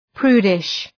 Προφορά
{‘pru:dıʃ}